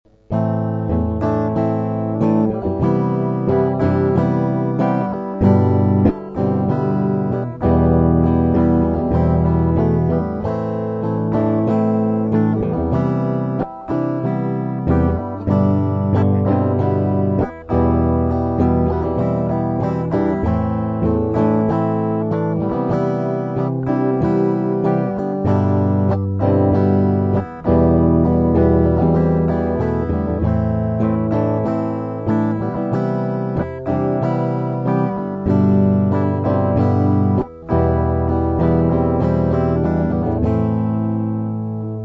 Проигрыш (Am - Cmaj7 - G6 - Em):